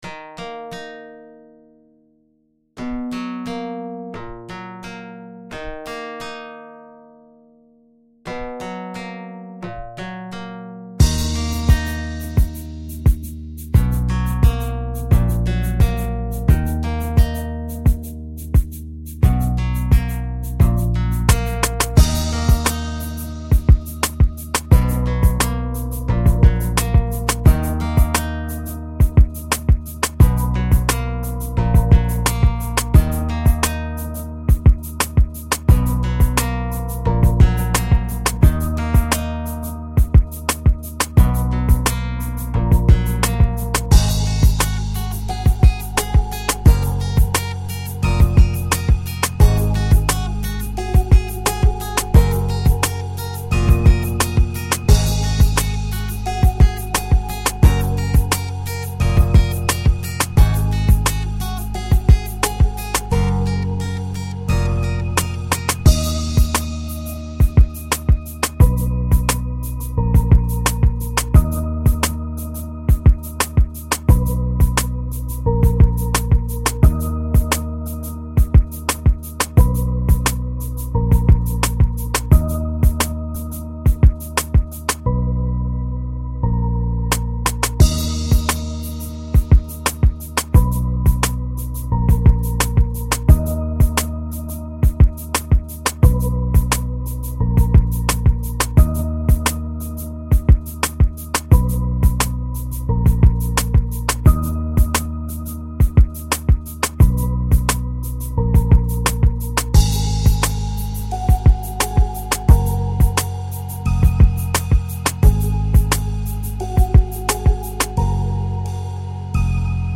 хип-хоп биты для рэпа на фоне